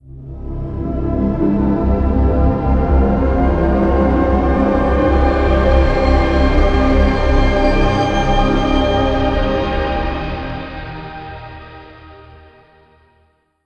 OS3 Warp 1.0 Startup.wav